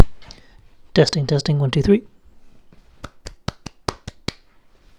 I am able to get sound out of the thing though a positive bias, but it remains a deeply unplesant scratch against a very faint signal.
1. Carbon filament audio test 2. Carbon filament audio test with a 4.7uf filter capacitor, no use